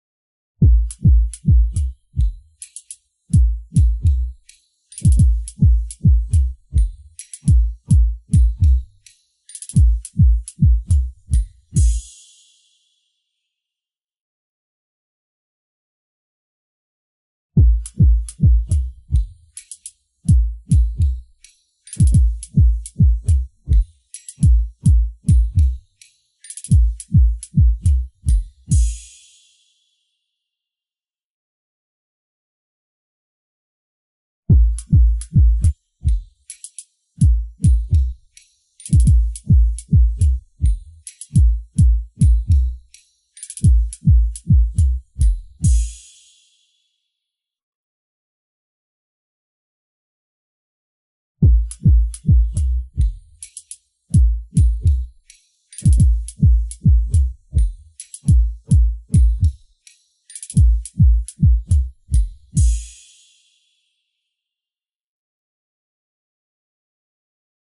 Animacija_Logo_Chistyj_metall-spaces.im_1__drums.mp3